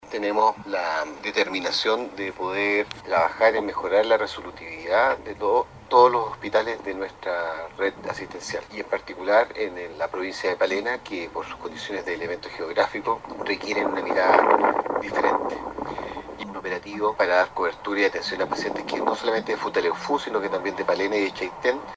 El director del Servicio del Servicio de Salud Del Reloncaví, Dr. Jorge Tagle, agradeció a los profesionales por su colaboración en la reducción de listas de espera.